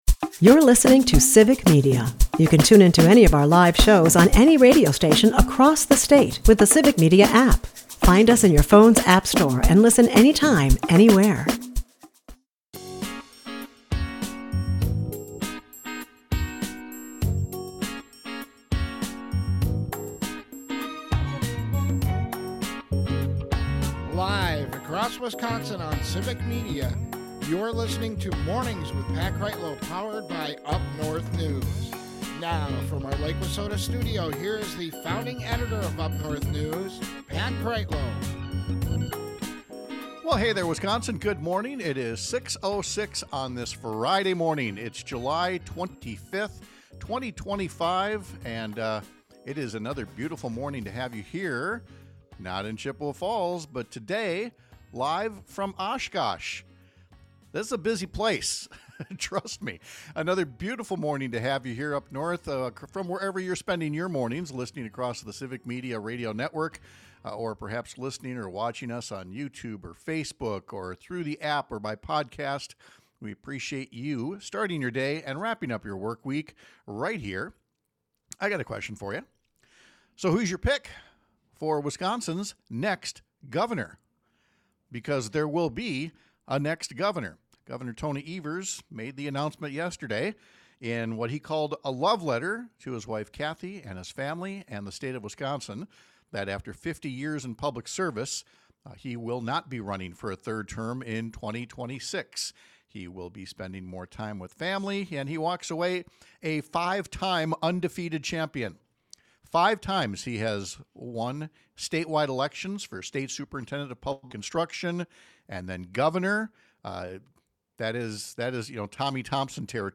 We bring the show back to Oshkosh, just in time to react to the news that Gov. Tony Evers will not run for a third term in 2026. We’ll get reaction from Sen. Tammy Baldwin and others to the announcement, which triggers wide-open primaries in both parties for next year’s crucial elections.